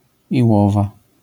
Ficheiro de áudio de pronúncia.